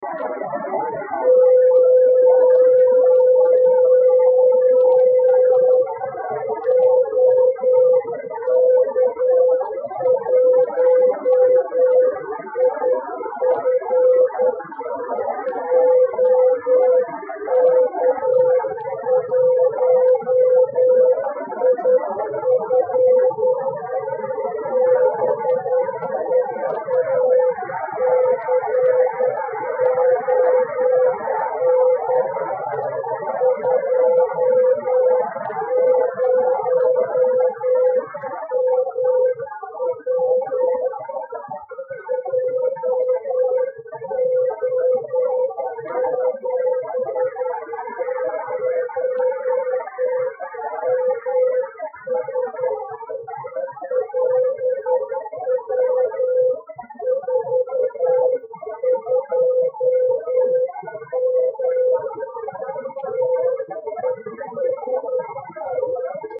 Když jsem pouštěl maják 20 mW, pak to nečinilo problémy vůbec nikomu.
Je to důkaz, že i v tak zarušeném pražském prostředí je to slyšet.